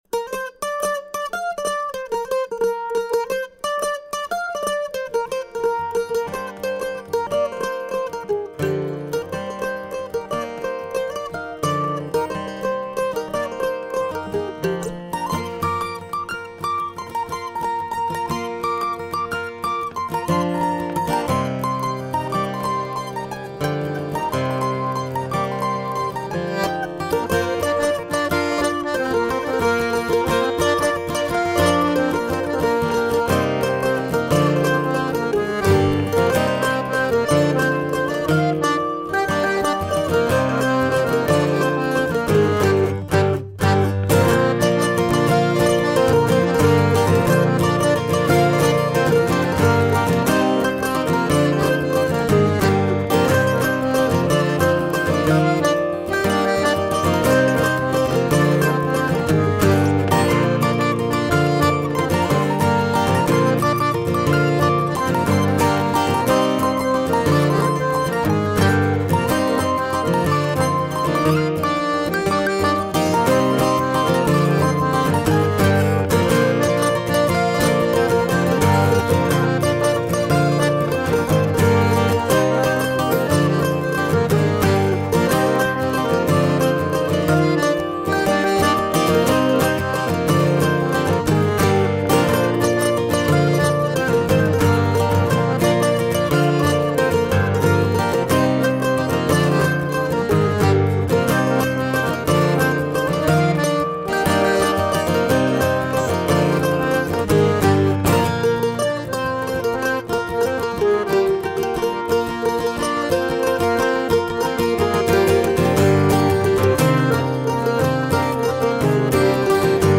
Banjo & Podorythmie, Accordion (feet tapping)
Guitar
Mandolin